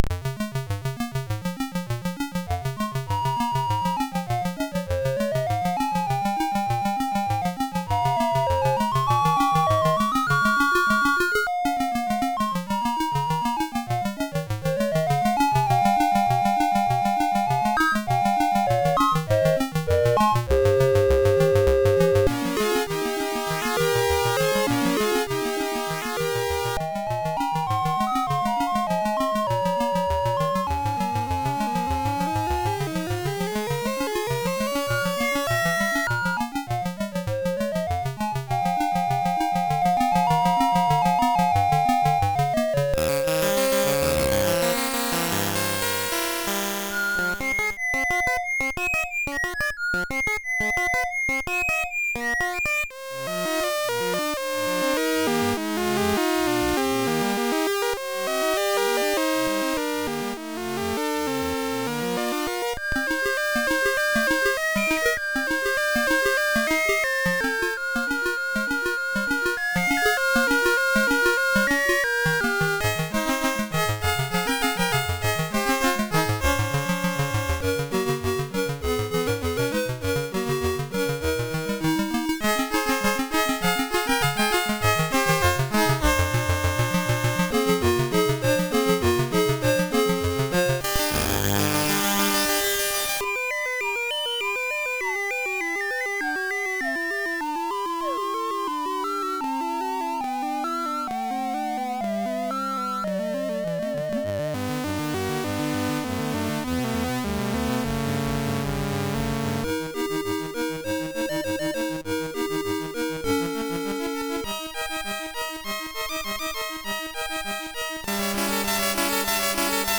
Gazette 1988 June / 1988-06.d64 / passepied.mus ( .mp3 ) < prev next > Commodore SID Music File | 2022-09-20 | 8KB | 1 channel | 44,100 sample rate | 3 minutes
passepied 1.mp3